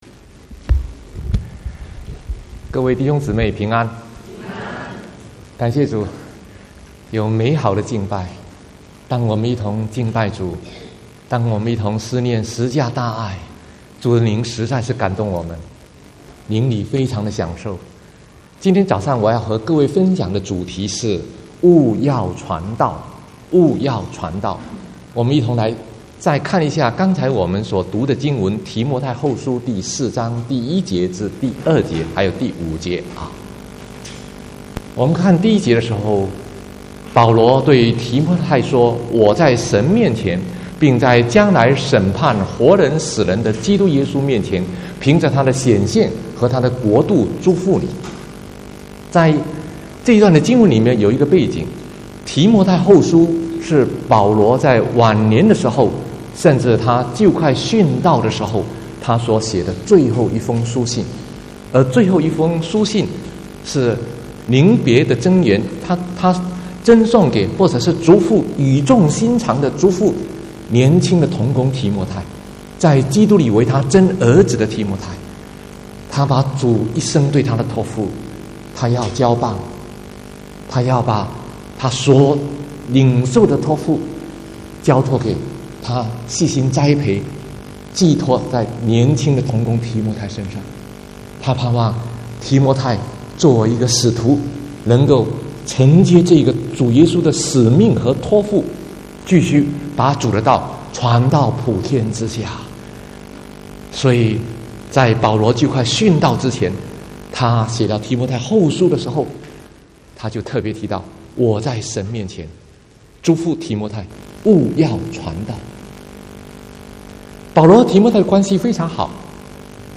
13/10/2019 國語堂講道